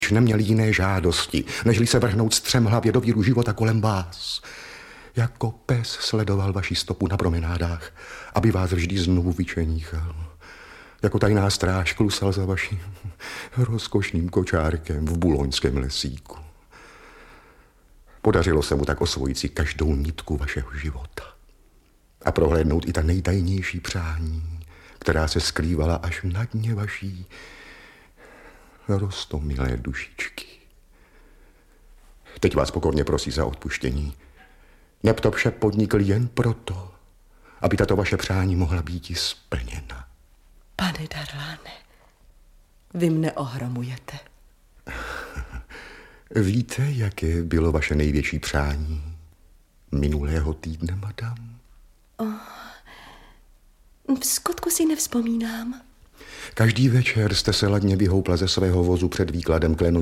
Audiobook
Read: Růžena Merunková